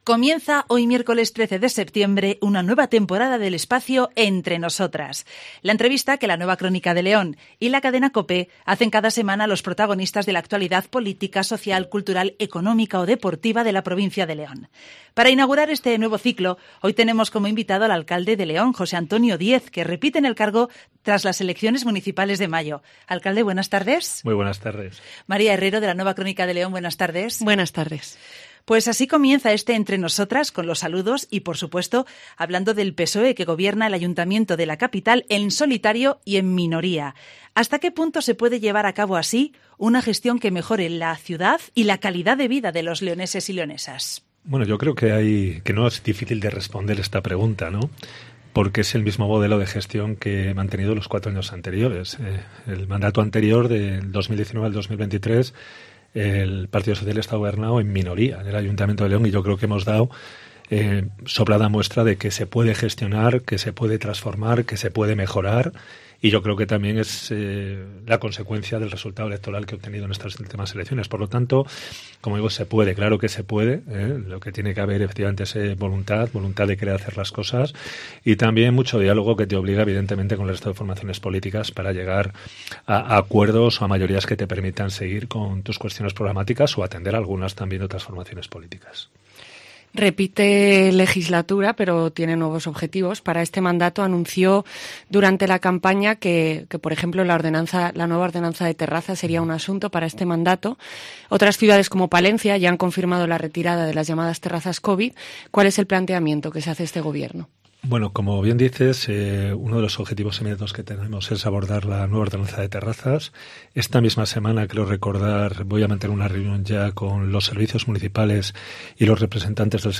Para inaugurar este nuevo ciclo hoy tenemos como invitado al alcalde de León, Jose Antonio Diez, que repite en el cargo tras las elecciones municipales de mayo.